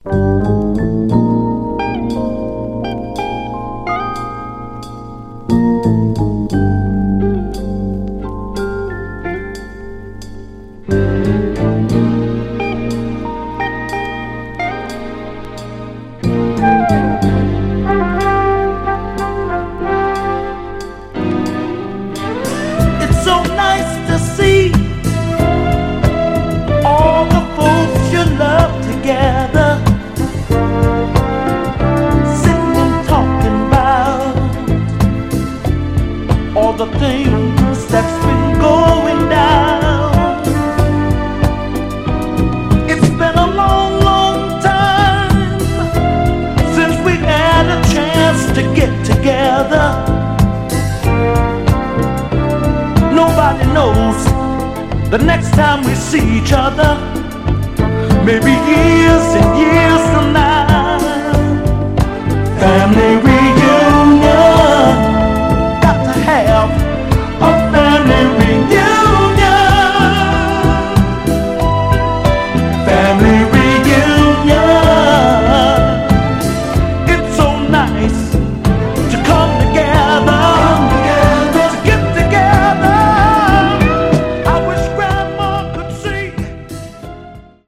メロウなフィリーソウル・クラシック！
※試聴音源は実際にお送りする商品から録音したものです※